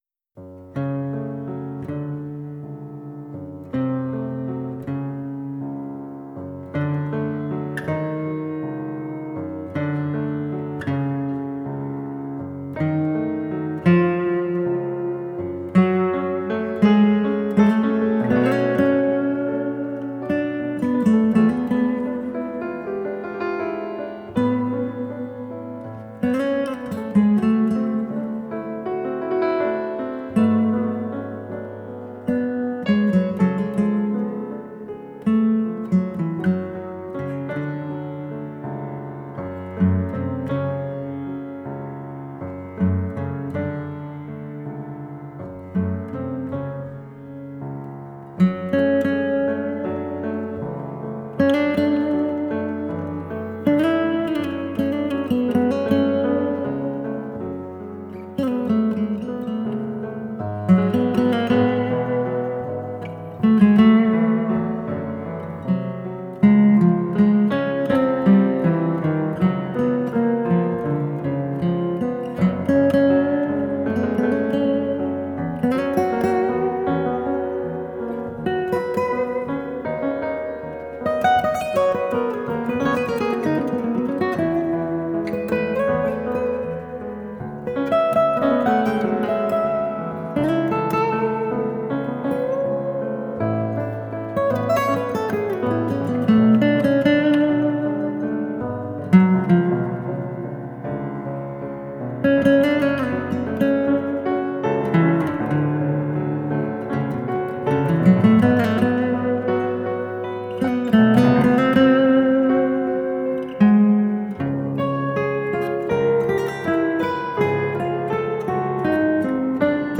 latin-orientált fúziós jazz